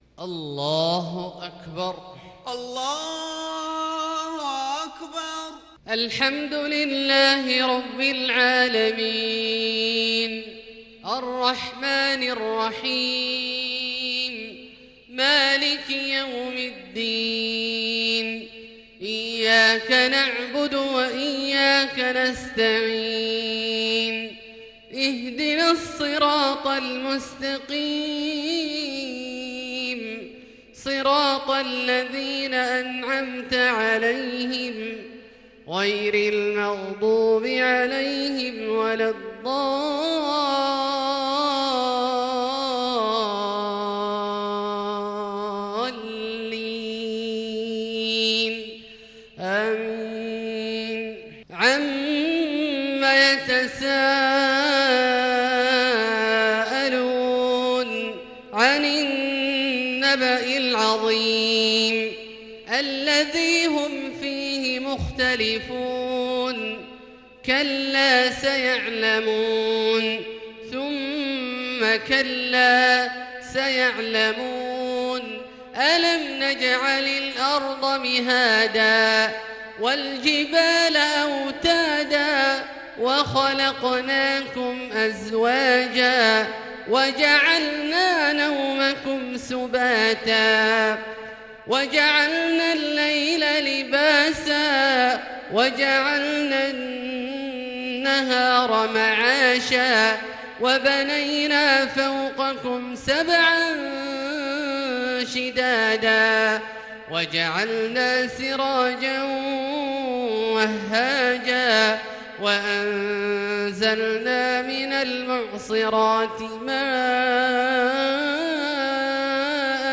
صلاة العشاء للقارئ عبدالله الجهني 2 ربيع الأول 1442 هـ
تِلَاوَات الْحَرَمَيْن .